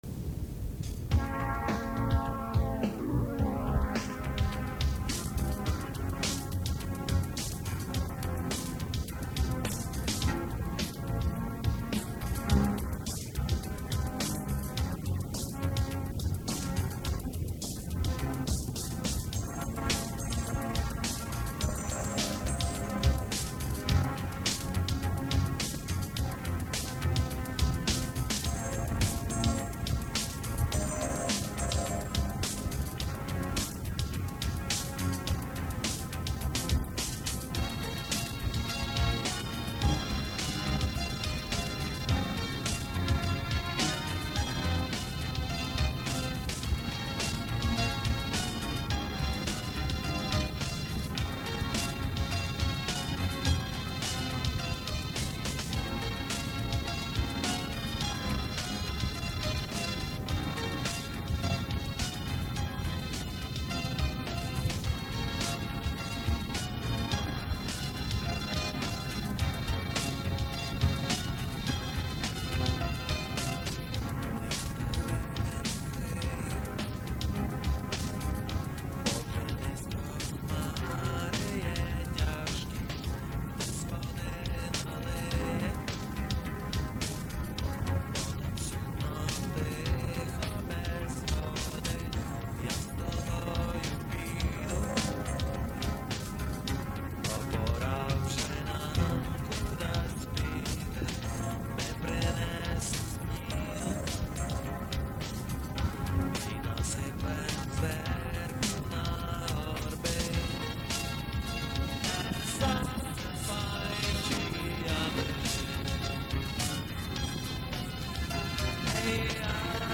Невидані треки, демо-версії та записи з концертів